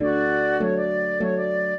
flute-harp
minuet0-10.wav